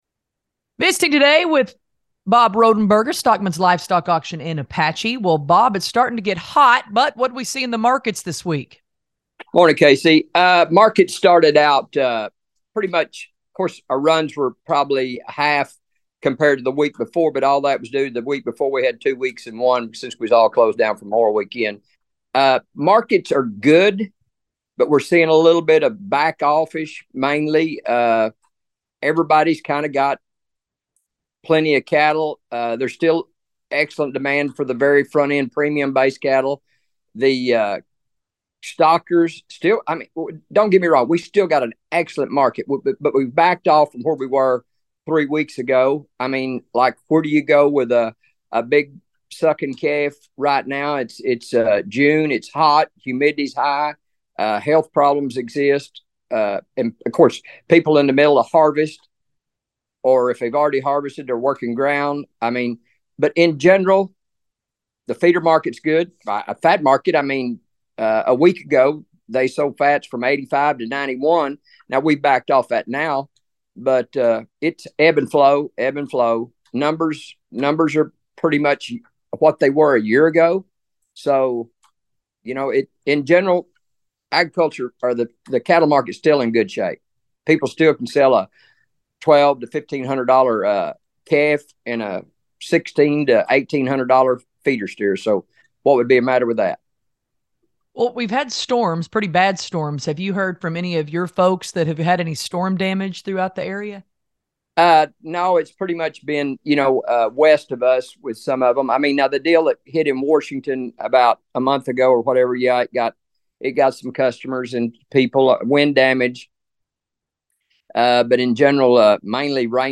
Here's our regular feature that is a part of the Monday Daily Email- market commentary